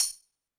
Drums_K4(19).wav